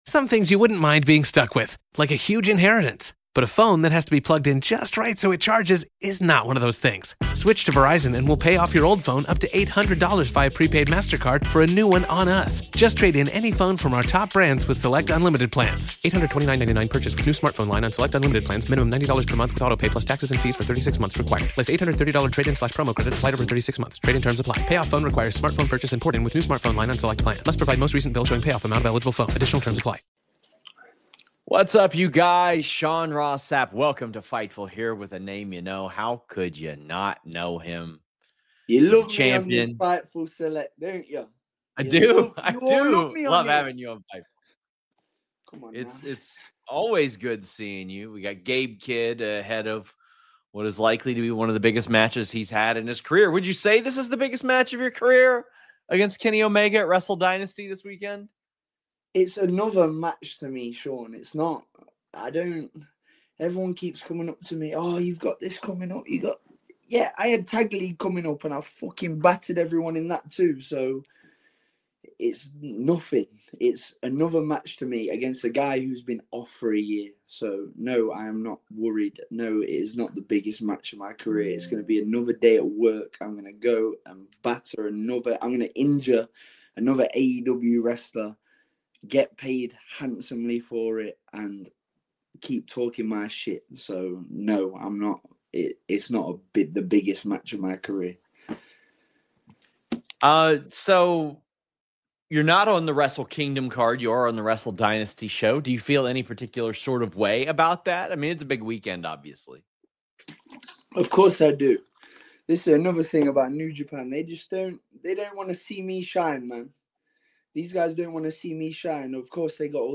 Shoot Interviews Jan 2